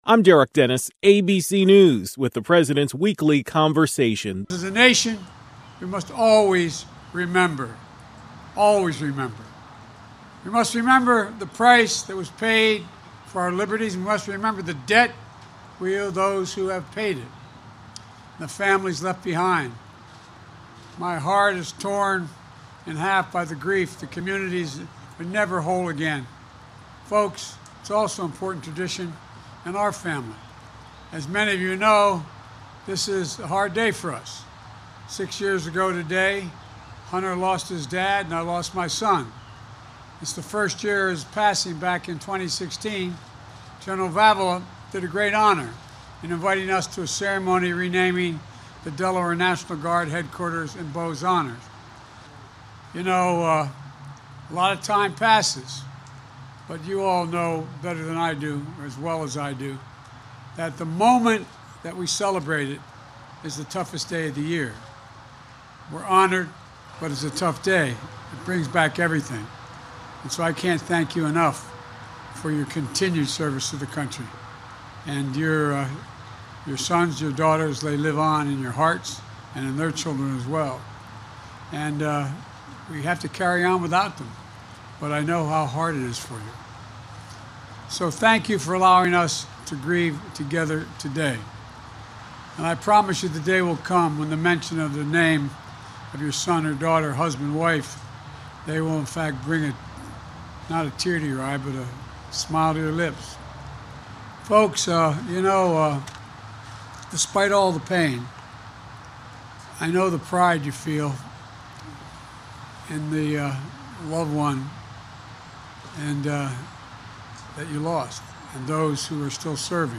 President Joe Biden delivered a Memorial Day message while visiting Veterans Memorial Park in New Castle, Delaware.